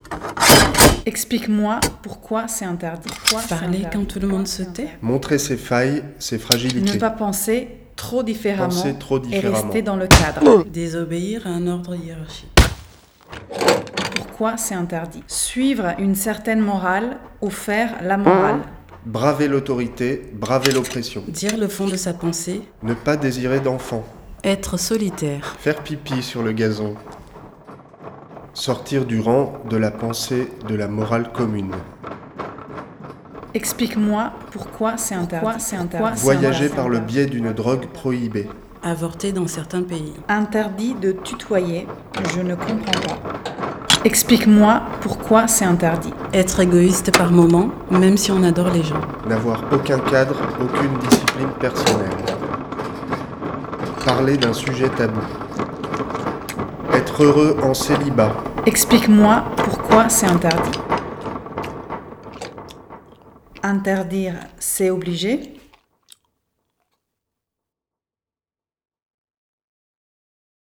Live
Le rendu devant le public de La Bifurk (Grenoble) était une proposition scénique minimaliste sur fond de sons transgression et de textes clamés.